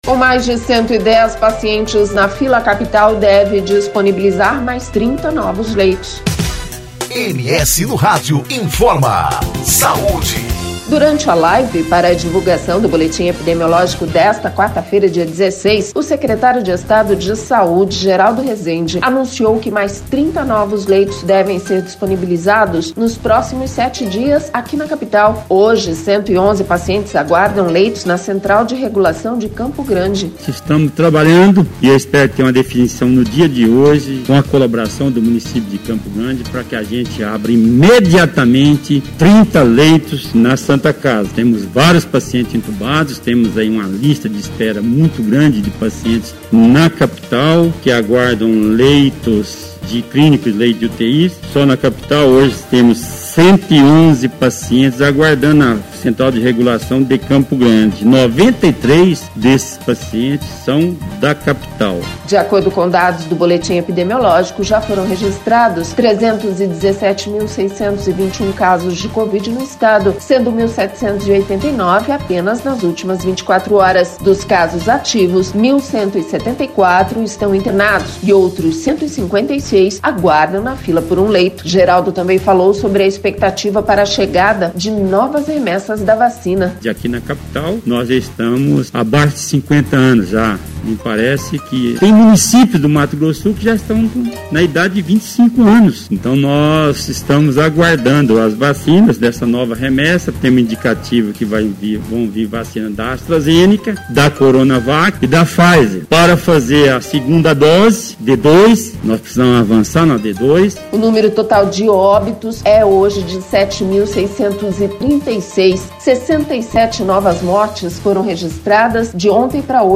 Durante a live para divulgação do boletim epidemiológico desta quarta-feira, dia 16, o secretário de Saúde Geraldo Resende anunciou que mais 30 novos leitos devem ser disponibilizados nos próximos sete dias, aqui na Capital.